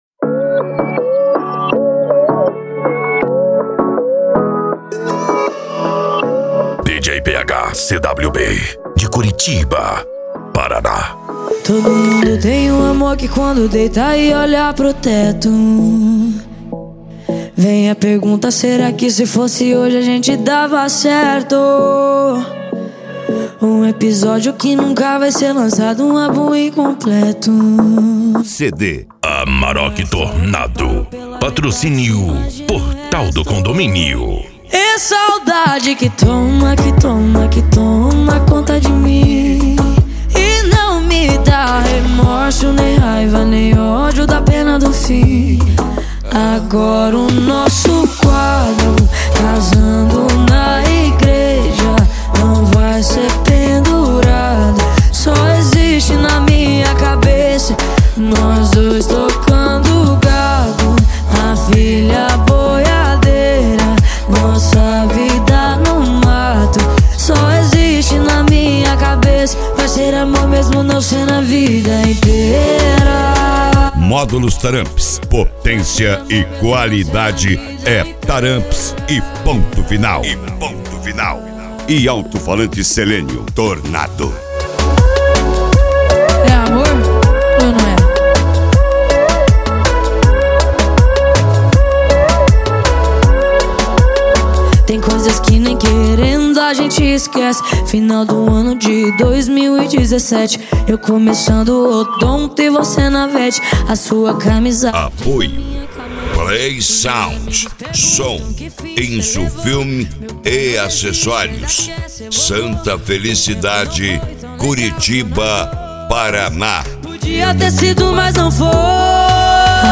Eletronica
Funk
PANCADÃO
SERTANEJO